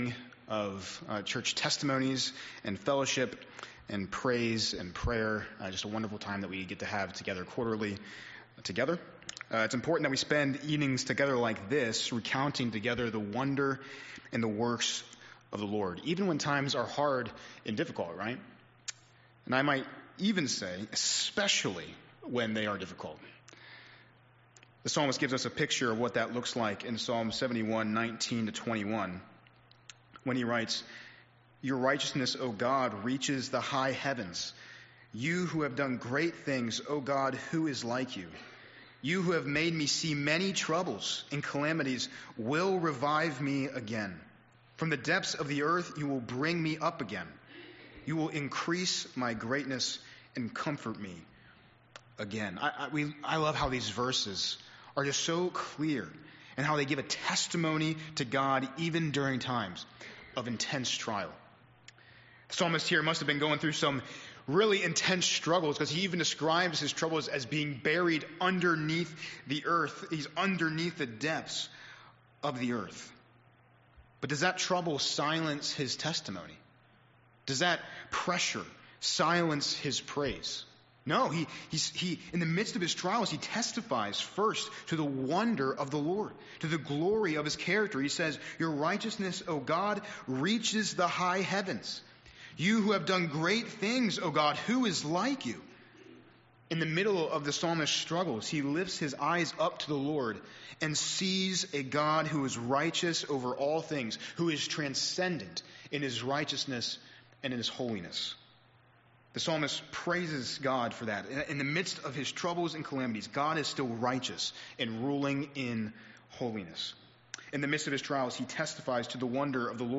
Praise & Testimony Service